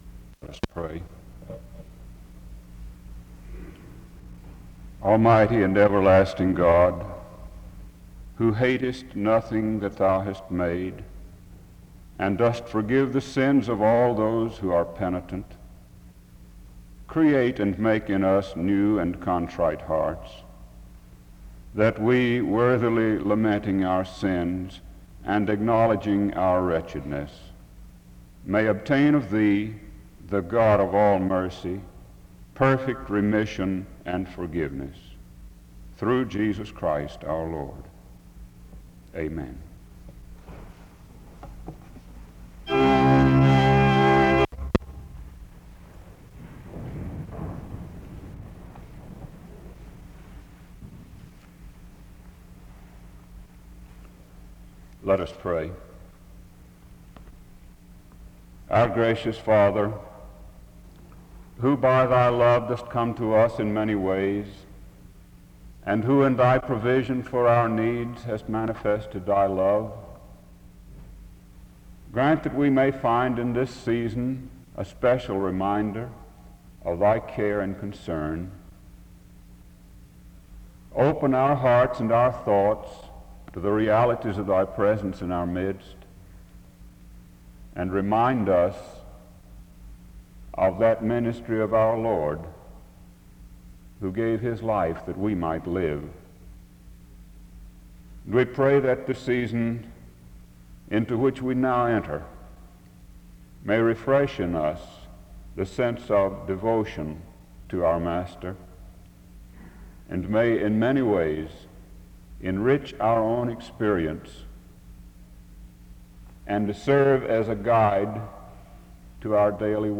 The service opens with a word of prayer from 0:00-0:39. A second prayer is given from 0:52-2:40.
He preaches on denying oneself to follow Christ. The service closes in a prayer from 20:52-21:01.